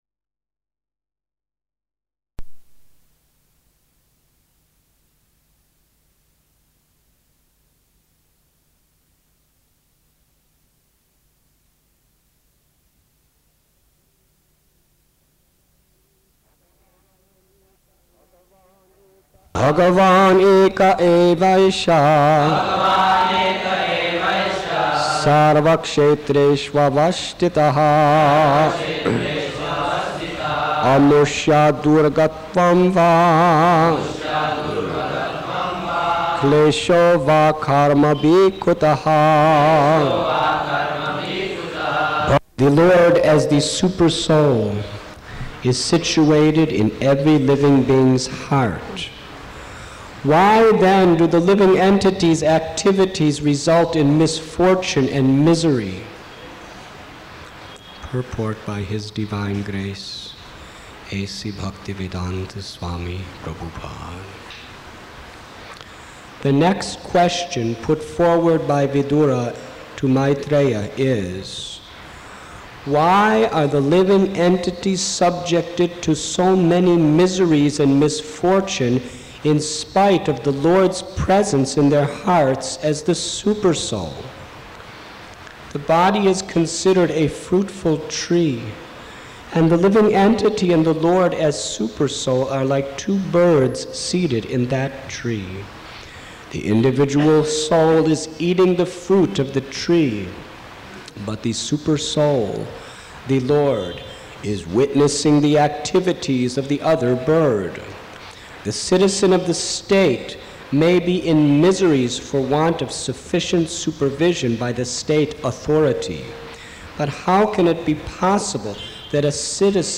SB 3.7 | Radhanath Swami Media | Radhanath Swami Lecture Download